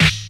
• 00's Hip-Hop Snare Sound D# Key 52.wav
Royality free snare drum sound tuned to the D# note. Loudest frequency: 2401Hz